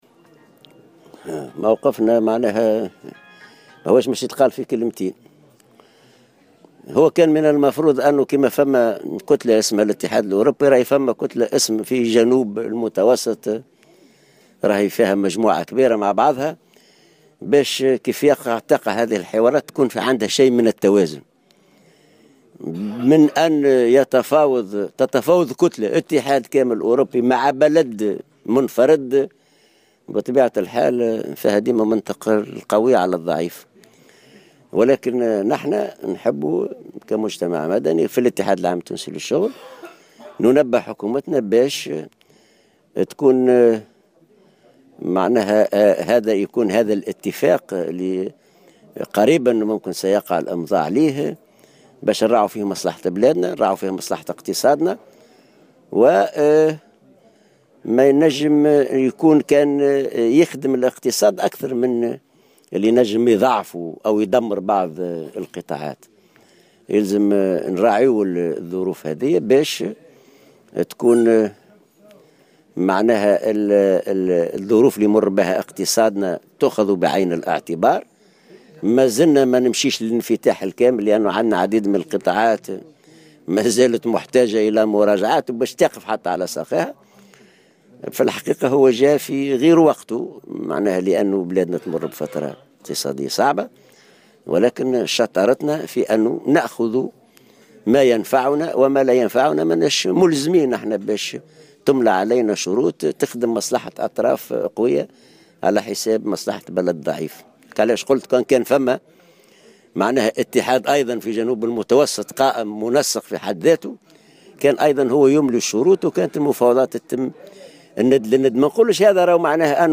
وأضاف العباسي على هامش مؤتمر النقابة العامة لأعوان الدفاع الوطني إنه كان من المفروض إحداث كتلة "جنوب المتوسط" تضم عددا من البلدان حتى لا يتم التفاوض مع بلد منفرد وحتى يكون هناك توازن بين الطرفين.